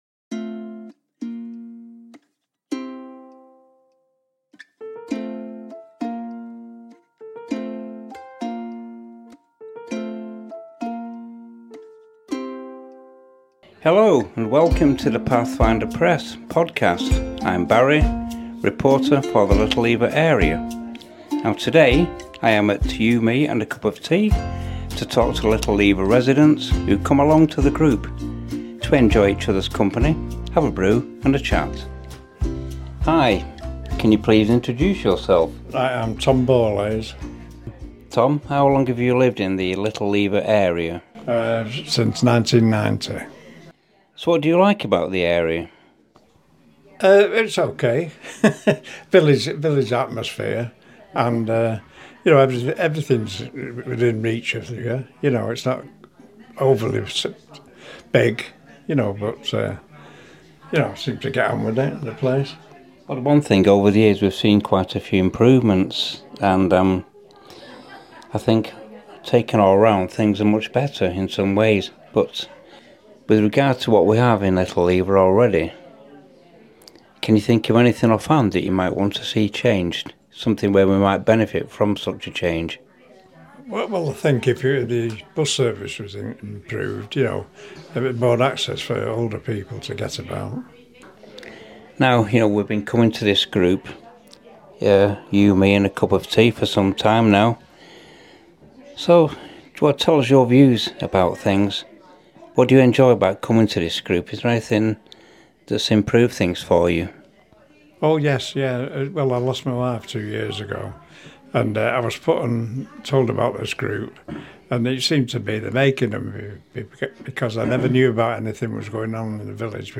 talks to Little Lever residents at a group organised by Age UK Bolton called You, Me and a Cup of Tea